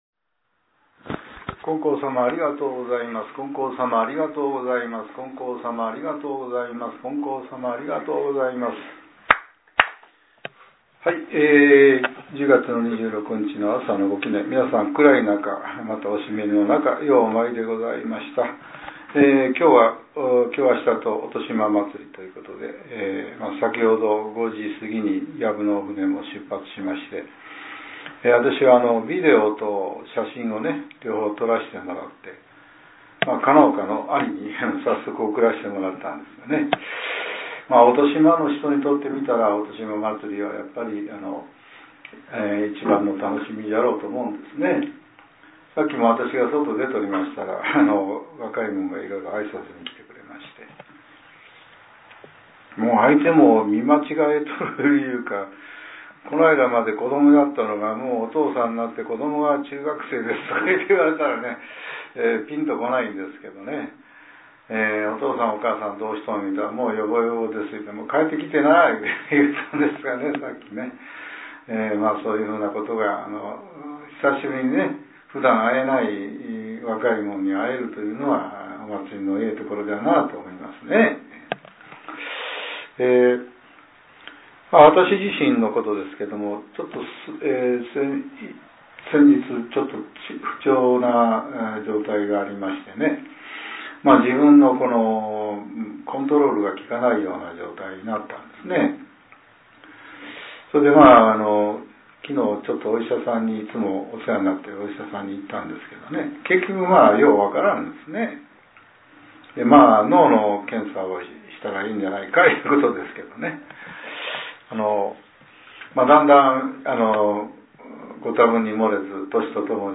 令和７年１０月２６日（朝）のお話が、音声ブログとして更新させれています。